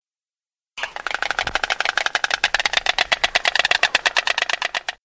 Cigogne blanche
Ciconia ciconia
cigogne.mp3